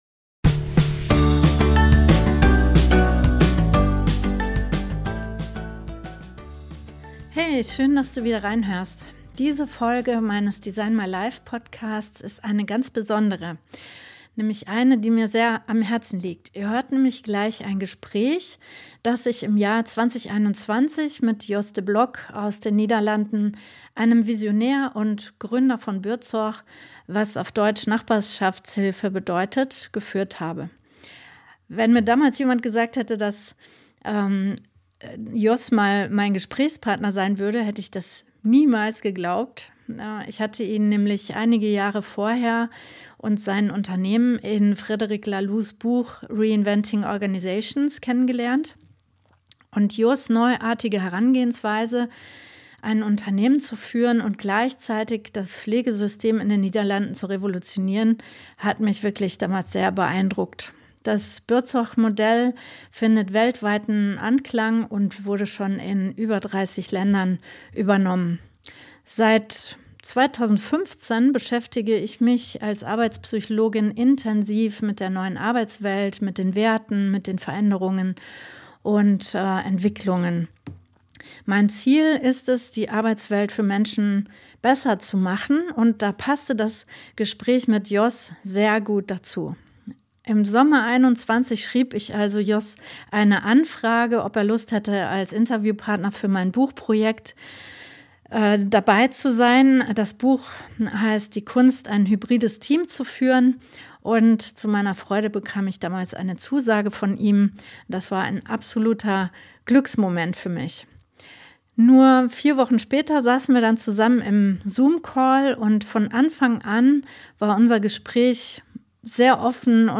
In unserem Gespräch gibt er Einblicke in sein Leben, seine Haltung, seine Vision für die Zukunft der Arbeit und die Prinzipien, die Buurtzorg so erfolgreich machen. Ein inspirierender Blick auf neue Wege in Pflege, Führung und gesellschaftlichen Wandel.